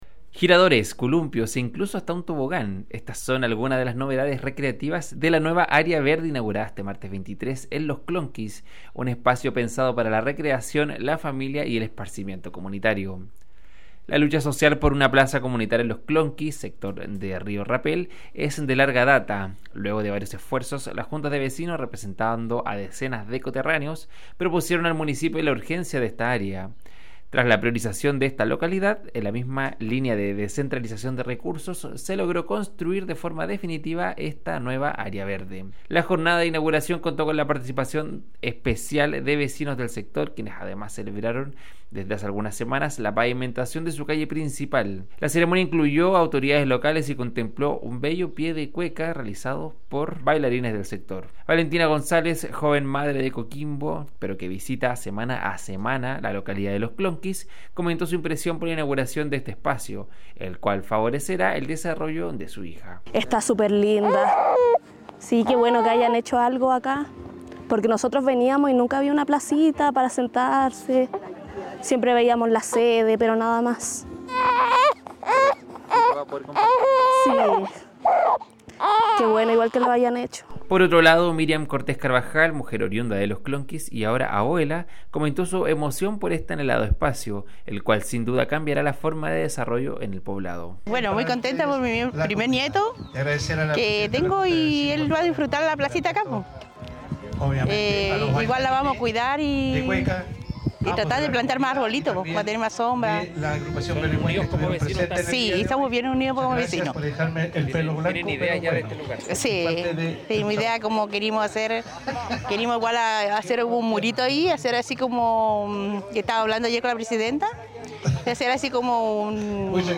DESPACHO_INAUGURACION-AREA-VERDE-LOS-CLONQUIS.mp3